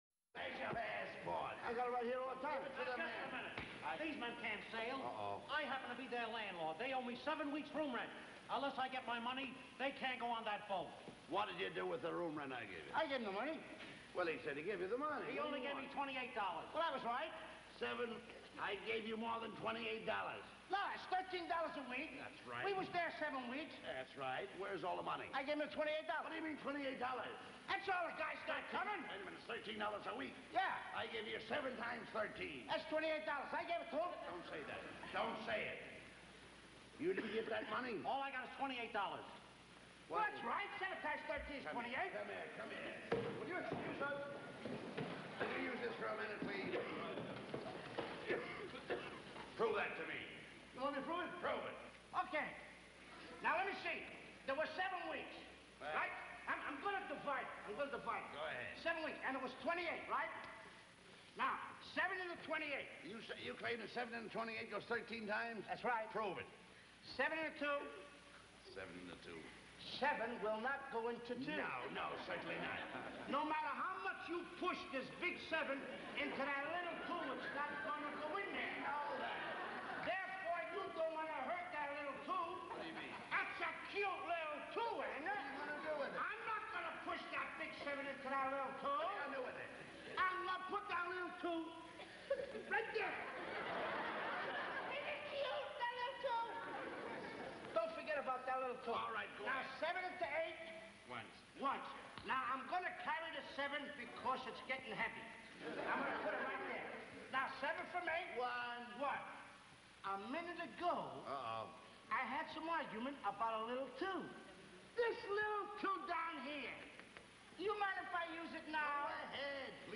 Bud Abbott and Lou Costello performing one of there famous Routines 13 x 7 =28